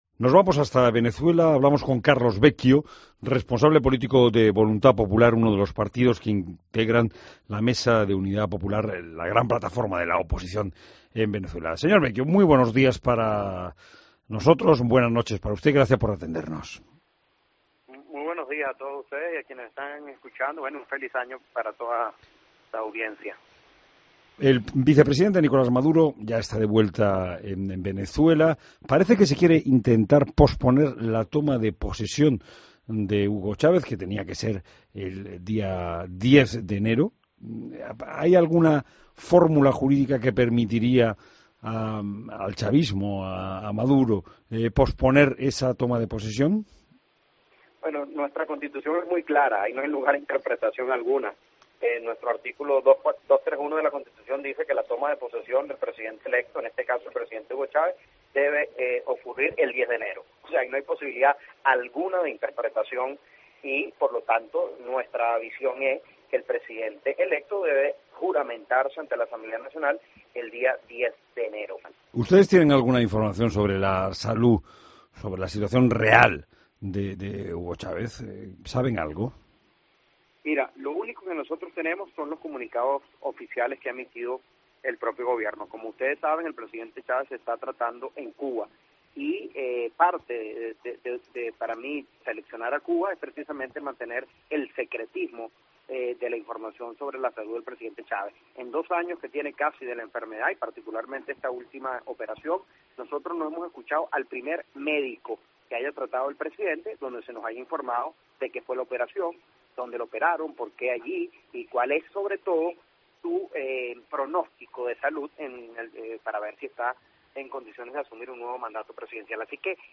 Entrevista a Carlos Vecchio, portavoz de la oposición en Venezuela